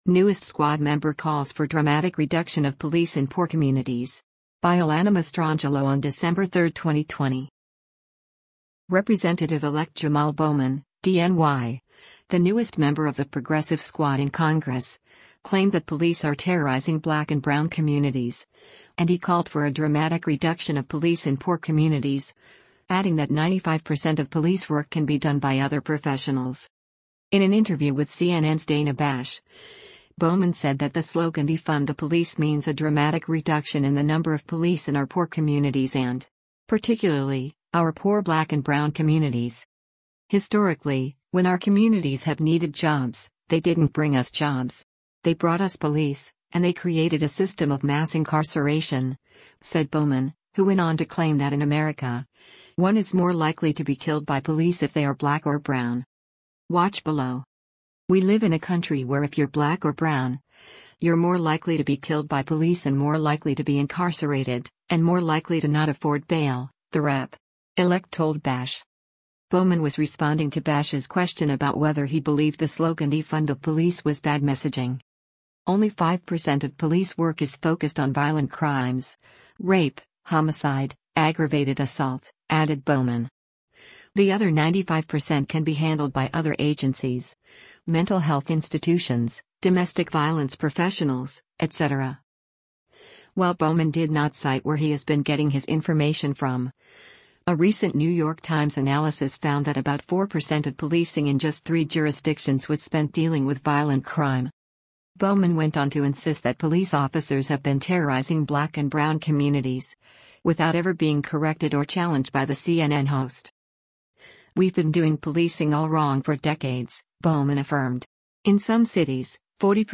In an interview with CNN’s Dana Bash, Bowman said that the slogan “Defund the Police” means “a dramatic reduction in the number of police in our poor communities and. particularly, our poor black and brown communities.”